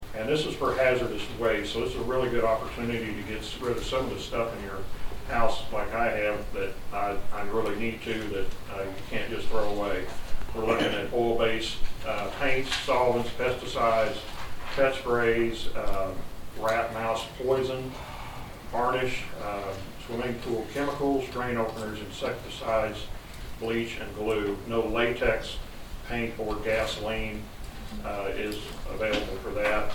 Marshall City councilman Dan Brandt detailed the list of items that will be accepted.